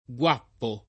[ gU# ppo ]